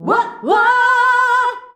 UAH-UAAH B.wav